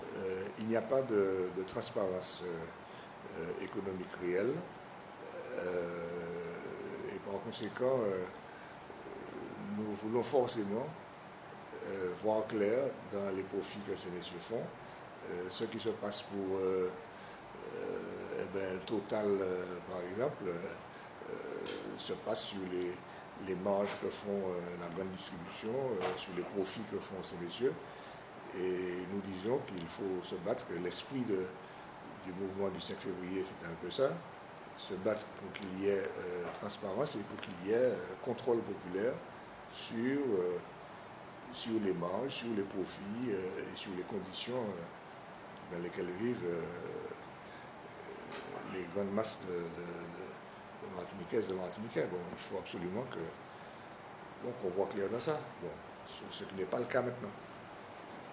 Interview à lire et à écouter.